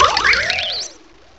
sovereignx/sound/direct_sound_samples/cries/ogerpon.aif at 5119ee2d39083b2bf767d521ae257cb84fd43d0e